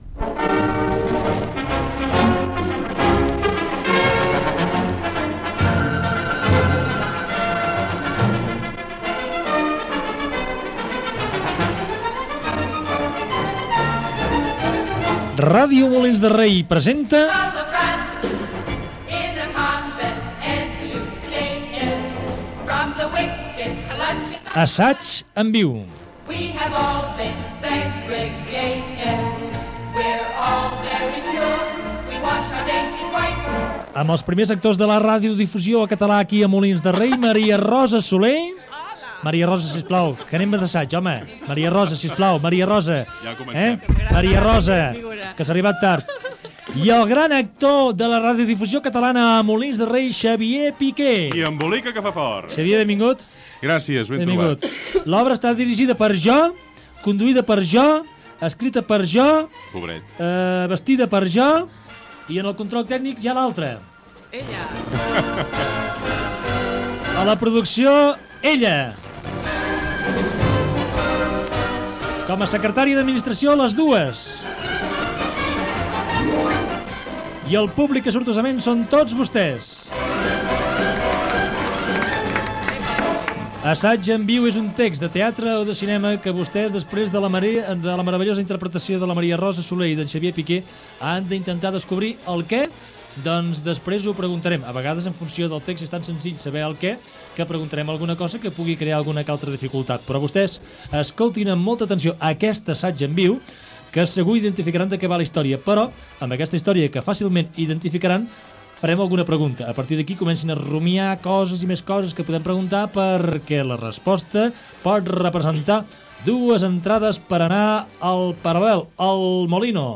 Espai "Assaig en viu" amb una ficció sonora cinematogràfica, trucada per encertar de quina pel·lícula es tractava Gènere radiofònic Entreteniment